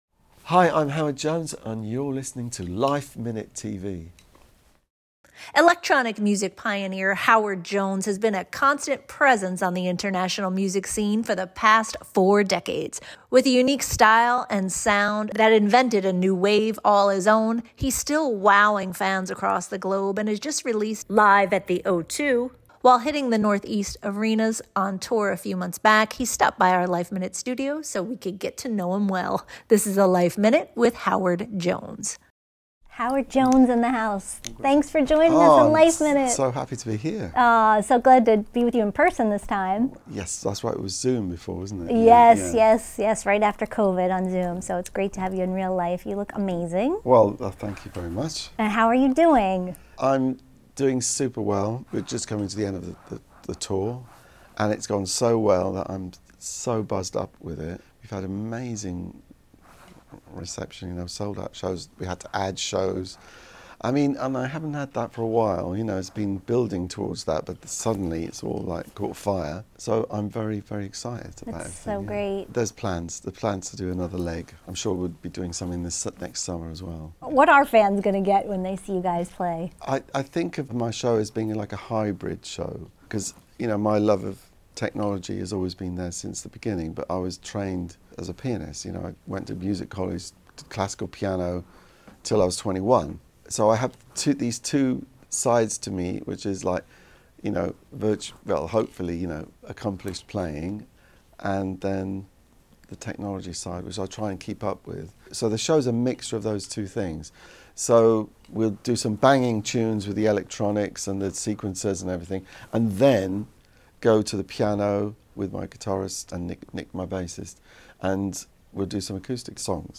While hitting the Northeast on tour a few months back, he stopped by our LifeMinute Studios to catch us up on all the goods he’s been up to.